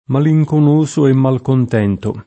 DOP: Dizionario di Ortografia e Pronunzia della lingua italiana
malinconioso [malijkonL1So] agg.